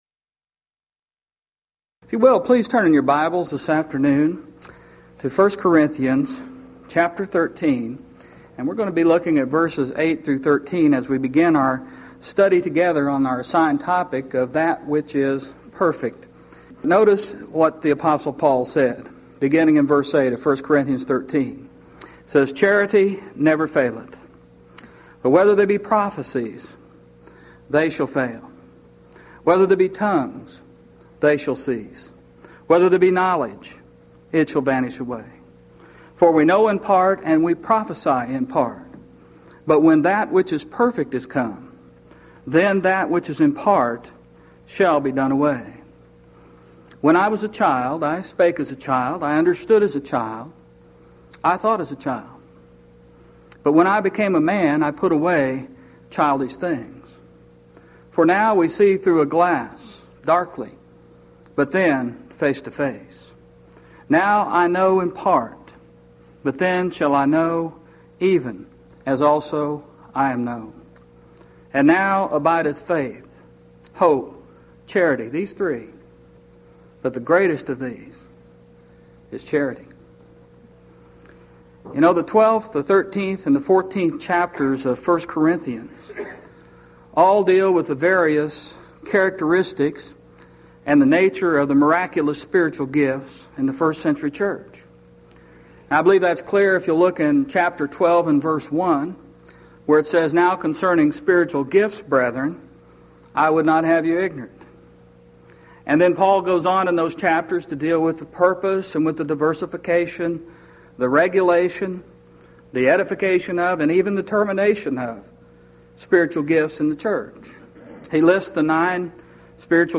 Event: 1997 Mid-West Lectures
lecture